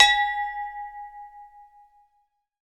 Index of /90_sSampleCDs/NorthStar - Global Instruments VOL-2/PRC_Agogo Bells/PRC_Agogo Bells